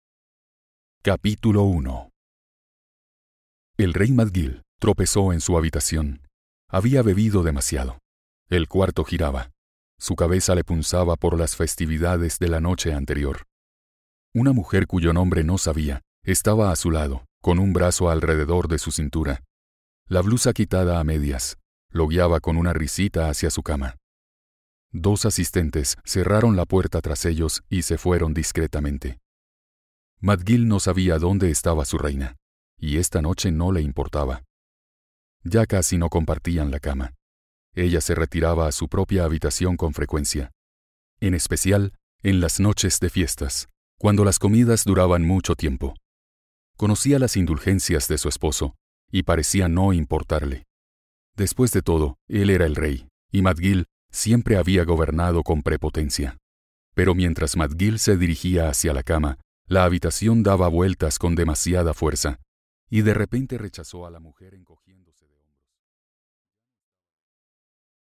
Аудиокнига La Marcha De Los Reyes | Библиотека аудиокниг